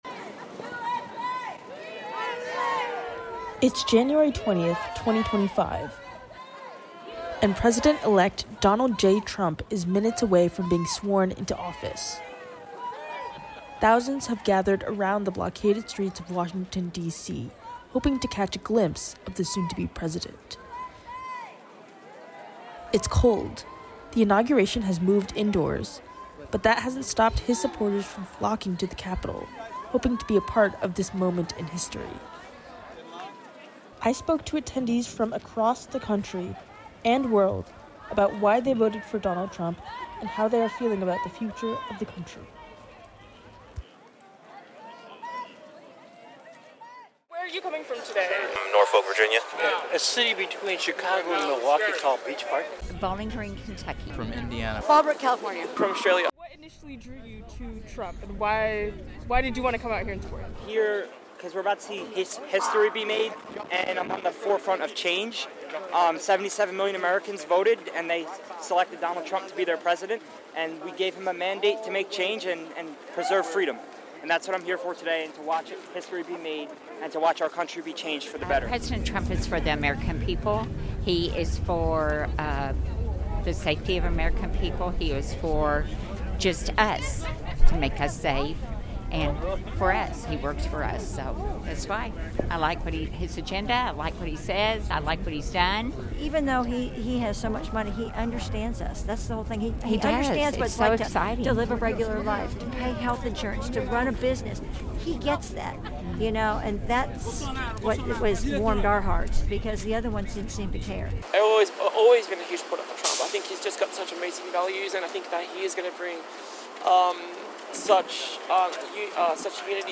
People from around the country came out to the frigid DC area to show their support for the now-President. Pavement Pieces spoke to people standing outside the Capitol building and waiting in line at Capital One Arena about how they were feeling, what made them support President Trump, and what they hope for for the future of the country.